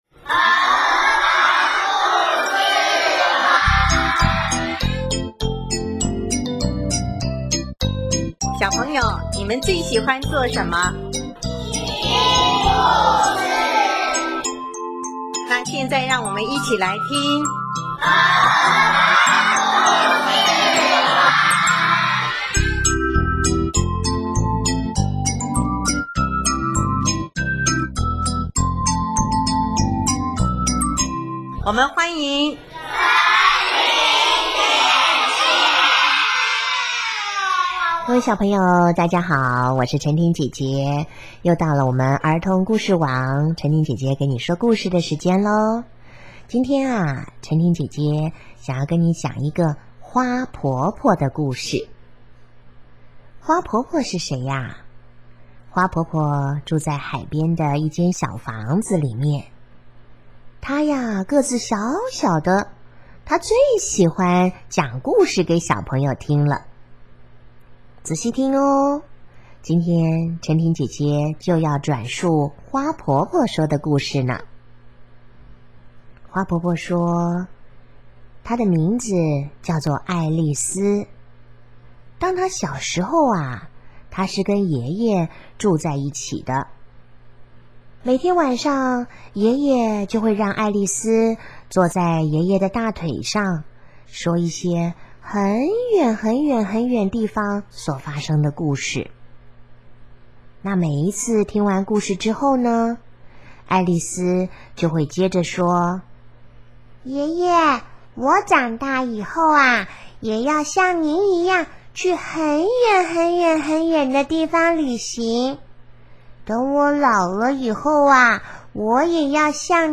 首页 / 家庭/ 儿童故事